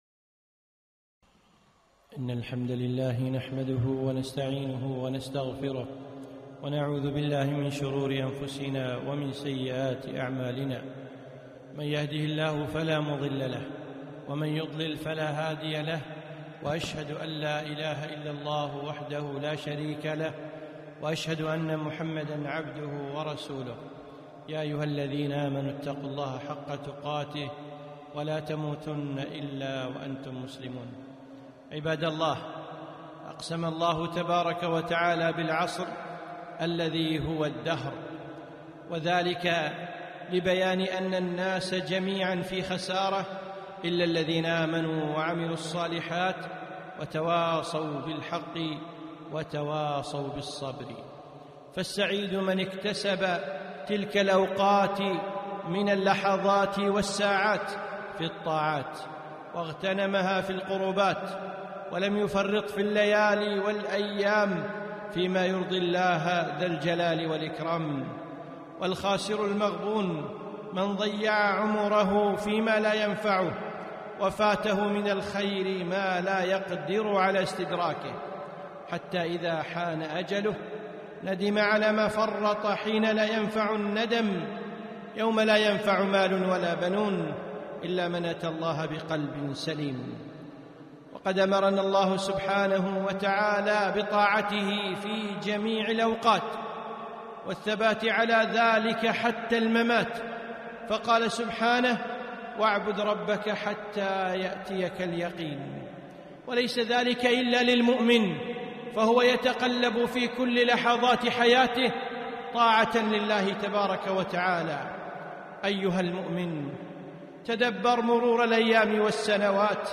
خطبة - استغلال الأوقات